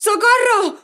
Mujer pidiendo ayuda: ¡Socorro! 1
exclamación
Sonidos: Voz humana